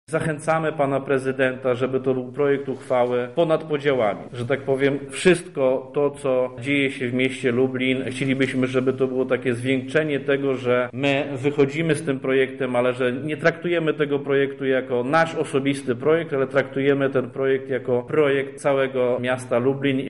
O tym, jaka jest istota dokumentu, mówi radny PiS, Piotr Breś.